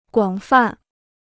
广泛 (guǎng fàn) Free Chinese Dictionary